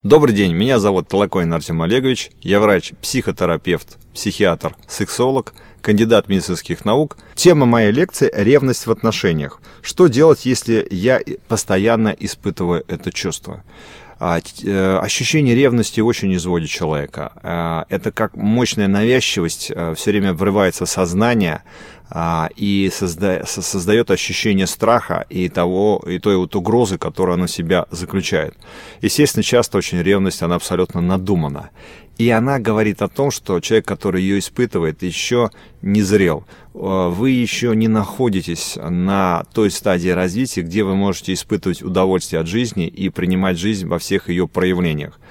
Aудиокнига Ревность в отношениях